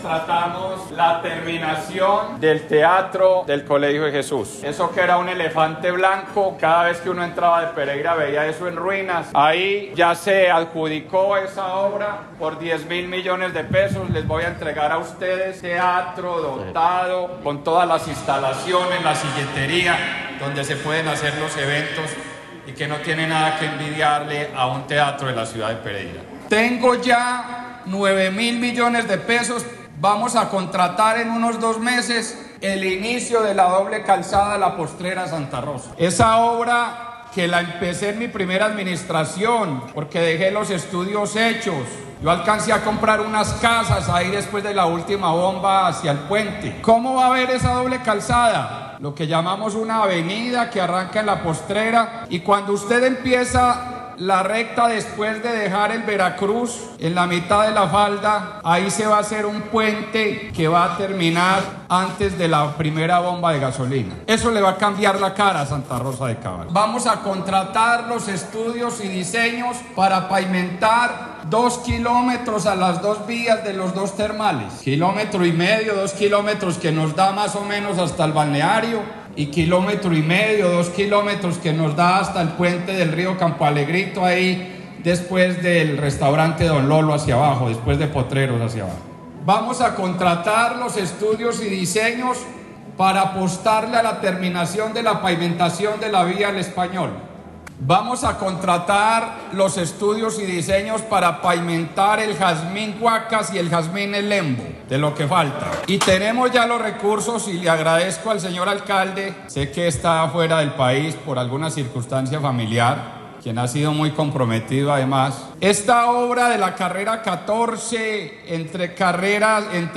Un gran balance en materia de infraestructura entregó el Gobernador Víctor Manuel Tamayo Vargas al liderazgo comunal de Santa Rosa de Cabal, donde se destaca la inversión para culminar el Teatro del Colegio de Jesús y su completa dotación; además, de obras viales como la doble calzada La Postrera – Santa Rosa, mitigación del riesgo e infraestructura educativa.